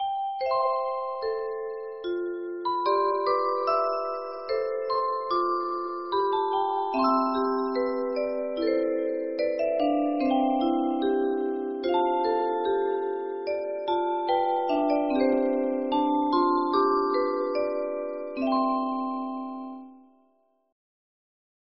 Music Box Songs,
Suitable for standard 18 note music box mechanims,
-Hand crank music box mechanism
- Wind up wooden music box,